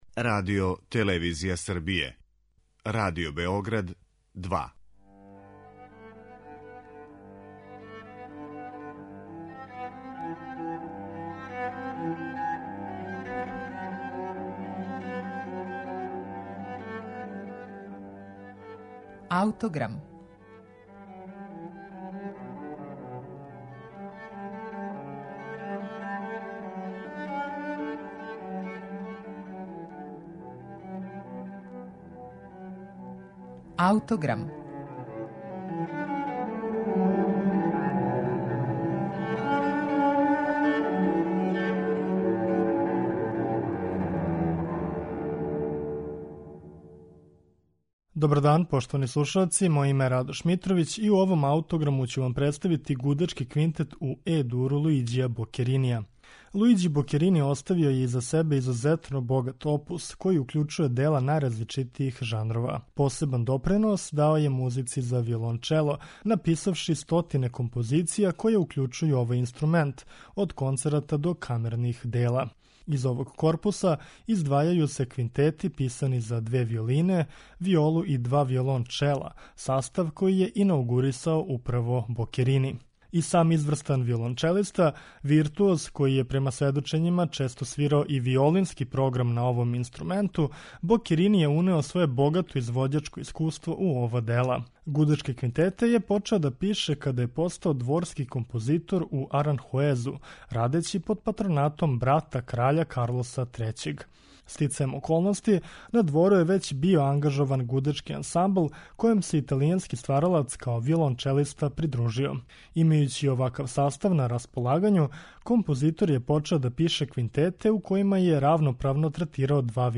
Из овог корпуса издвајају се квинтети писани за две виолине, виолу и два виолончела - састав који је инаугурисао управо Бокерини.
У Аутограму ћемо вам представити његов Квинтет у Е дуру у извођењу чланова састава Величанствена дружина.